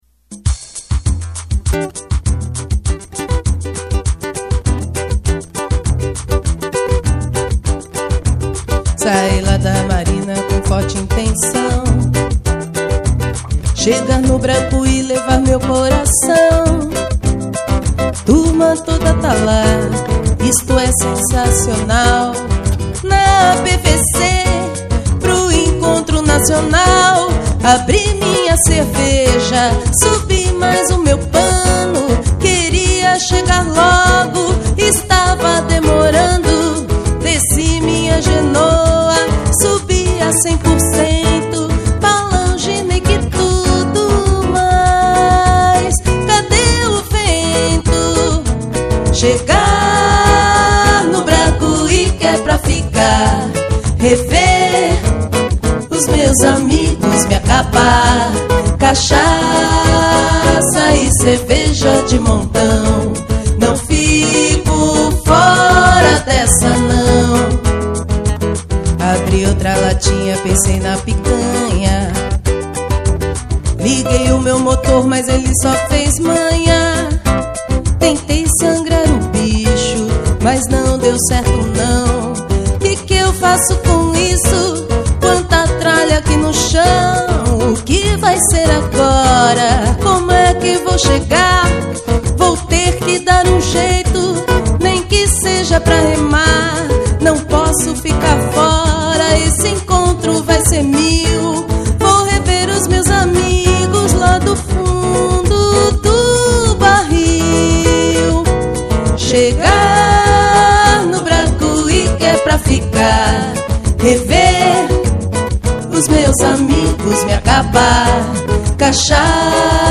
sambaencontro.mp3